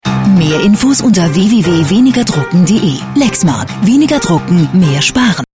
Vielseitige Profi-Sprecherin deutsch: Werbung, TV-Trailer und voice over für VOX, Phoenix.
Sprechprobe: Industrie (Muttersprache):
german female voice over artist.